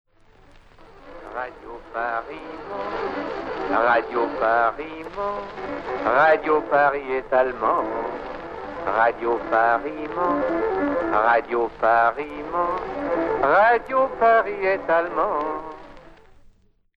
Louis Armstrong La Cucaracha Sur la BBC, Pierre Dac a parodié cette chanson avec les paroles suivantes :
Pierre Dac - Radio Paris ment - de Radio Londres.mp3